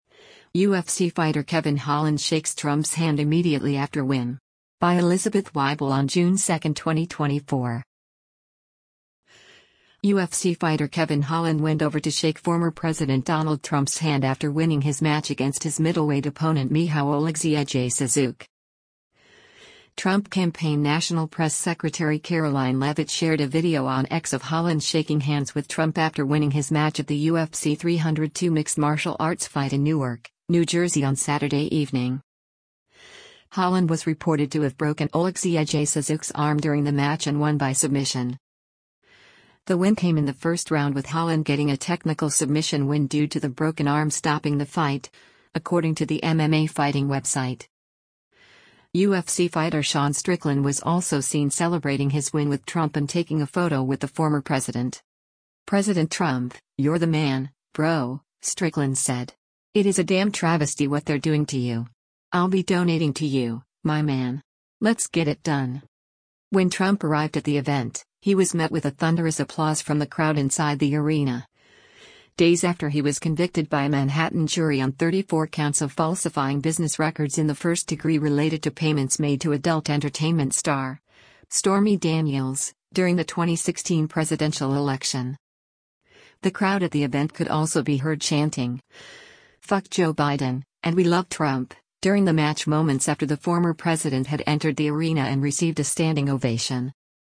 Trump campaign National press secretary Karoline Leavitt shared a video on X of Holland shaking hands with Trump after winning his match at the UFC 302 mixed martial arts fight in Newark, New Jersey on Saturday evening.